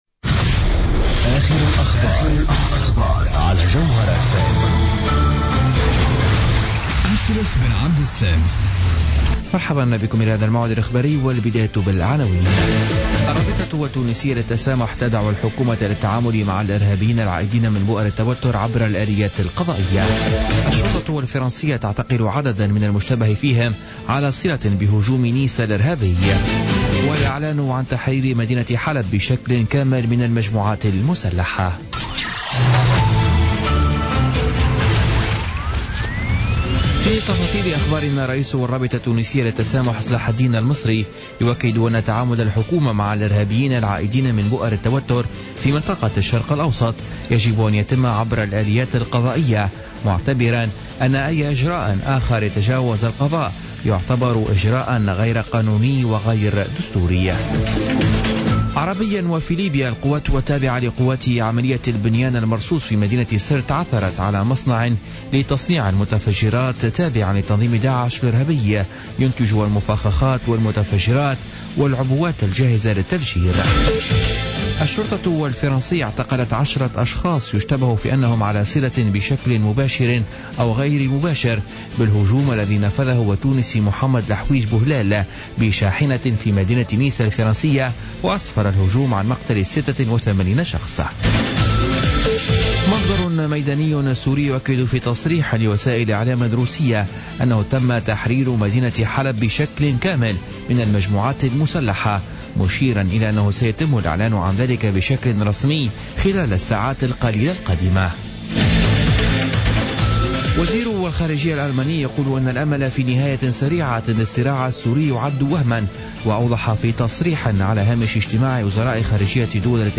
نشرة أخبار منتصف الليل ليوم الثلاثاء 13 ديسمبر 2016